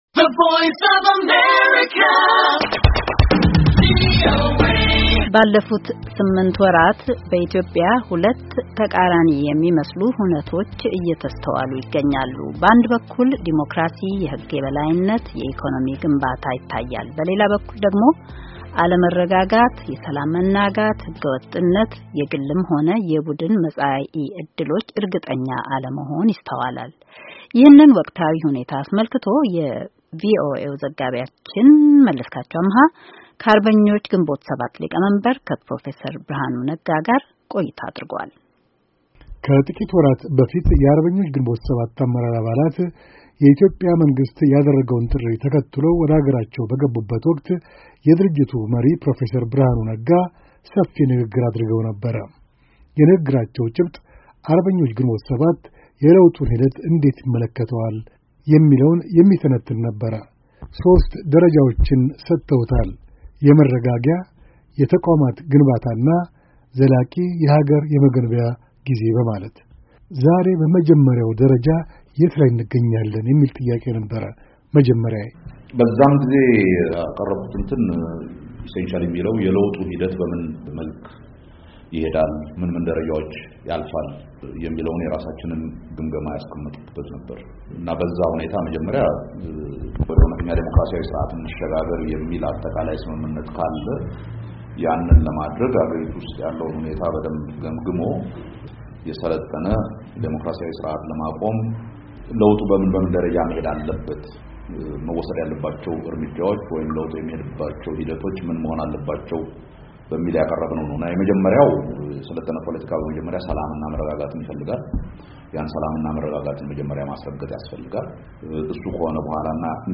ከአርበኞች ግንቦት 7 ሊቀመንበር ፕሮፌሰር ብርሃኑ ነጋ ጋር የተደረገ ቆይታ